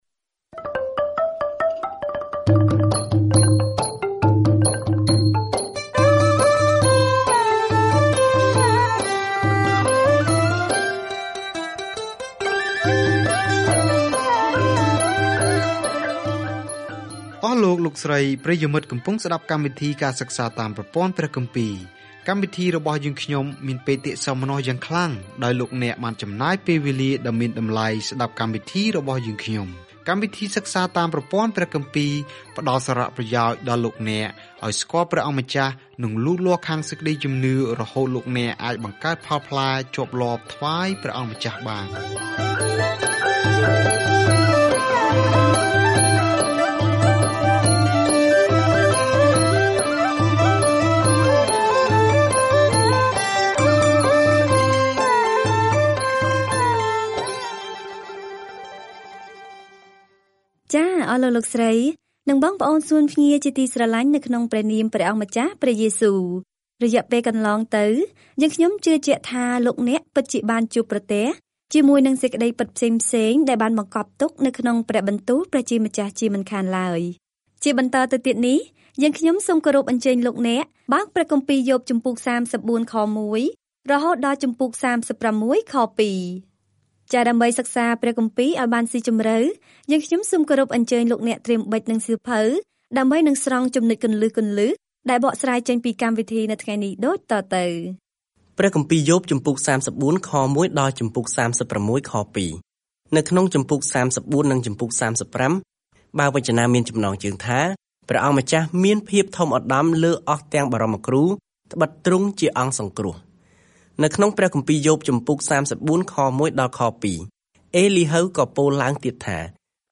ការធ្វើដំណើរជារៀងរាល់ថ្ងៃតាមរយៈយ៉ូប ពេលអ្នកស្តាប់ការសិក្សាជាសំឡេង ហើយអានខគម្ពីរដែលបានជ្រើសរើសពីព្រះបន្ទូលរបស់ព្រះ។